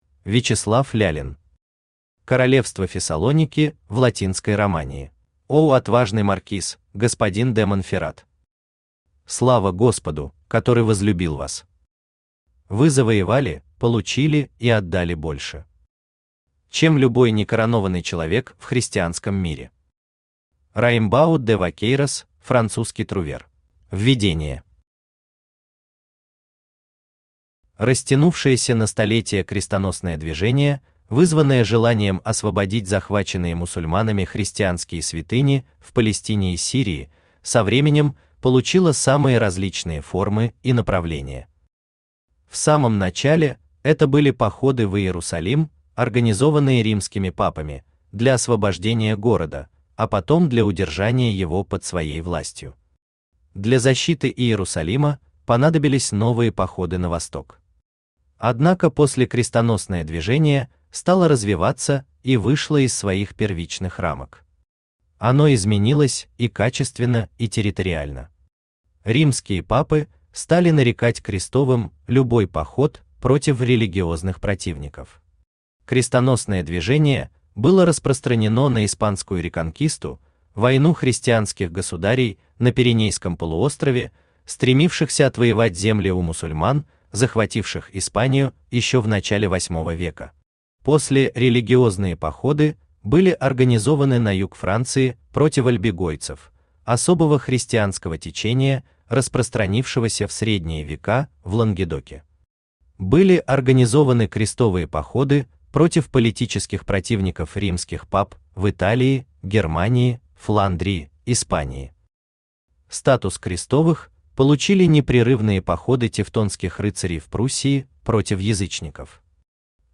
Аудиокнига Королевство Фессалоники в Латинской Романии | Библиотека аудиокниг
Aудиокнига Королевство Фессалоники в Латинской Романии Автор Вячеслав Егорович Лялин Читает аудиокнигу Авточтец ЛитРес.